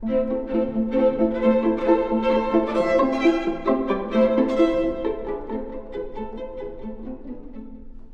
全体的には、透明度が高く、ふんわりとした軽い雰囲気です。
↑古い録音のため聴きづらいかもしれません！（以下同様）
民族感たっぷりのスケルツォです。
ヘミオラ（hemiola…3拍子×2小節の中に、2拍×3の拍子感を入れて特徴的なリズムを作る）や、スルポン（sul ponticello…あえて楽器の駒近くで演奏し、ちょっと軋むような音を出す）など、たくさんのリズム技法・演奏技法が出てきます。
バイオリン2本、ビオラ1本という、この曲でしか味わえない感覚のある曲です。